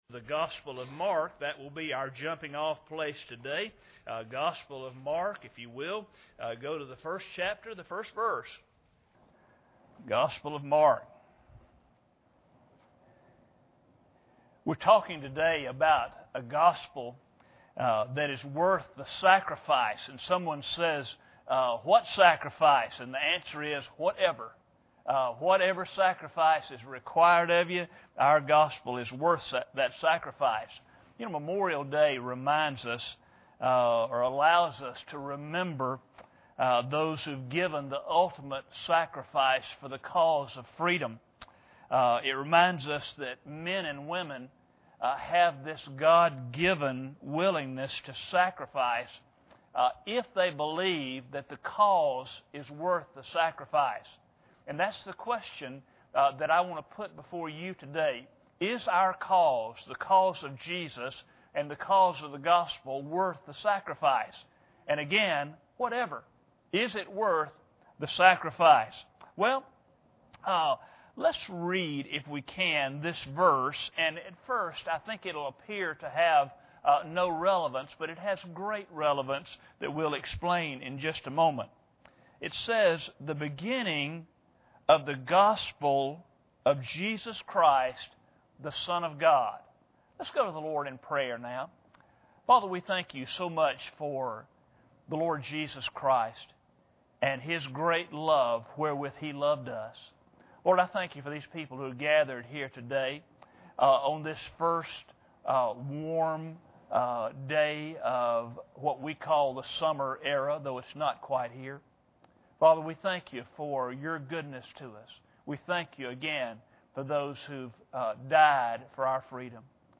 Mark 1:1 Service Type: Sunday Morning Bible Text